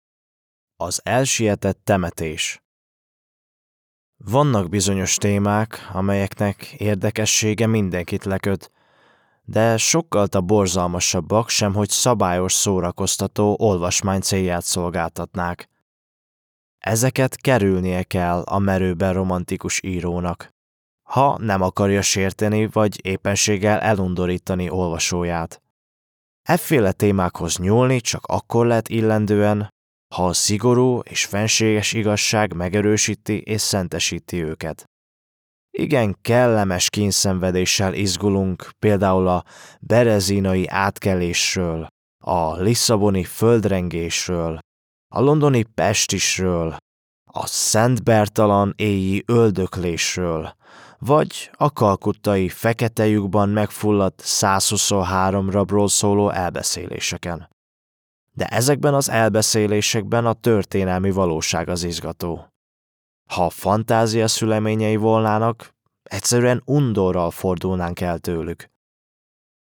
Male
Adult (30-50)
My voice is warm, relaxed, clear and versatile, with a natural mid-to-deep tone that works perfectly for commercials, corporate narration, e-learning, audiobooks and character work.
Audiobooks
Mysterious Audiobook Intro
All our voice actors have professional broadcast quality recording studios.
1002HungarianNarrationAudiobook.mp3